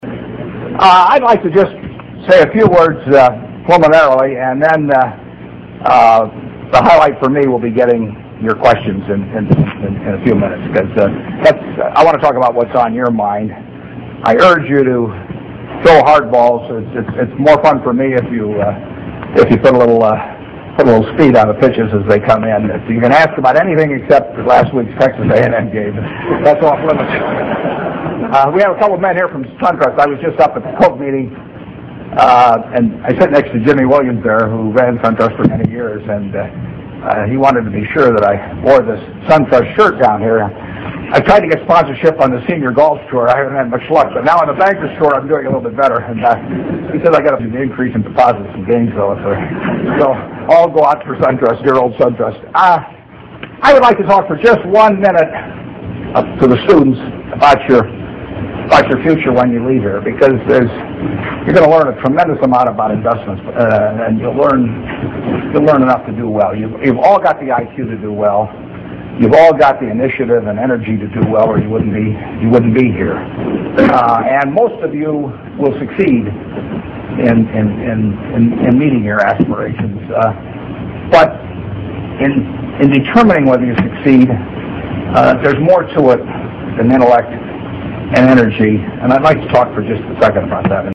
财富精英励志演讲25：巴菲特:决定你成功的因素(1) 听力文件下载—在线英语听力室